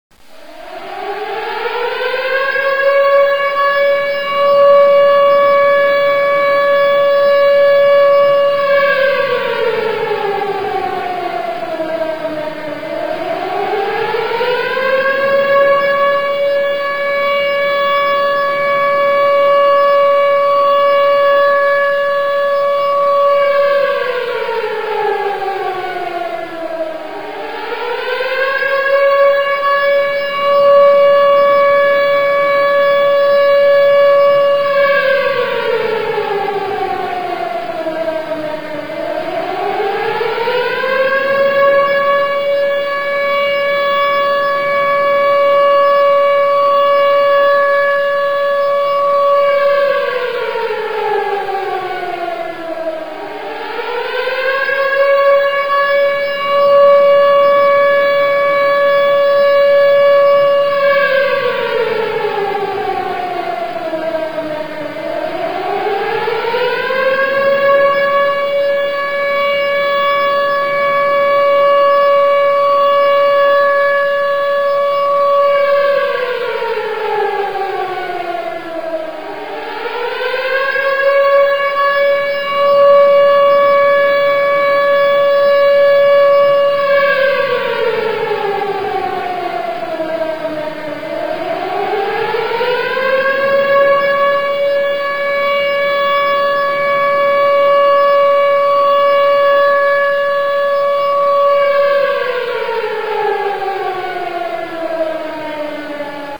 Siren Sesleri
2 Dakikalık Siren Sesleri için tıklayınız.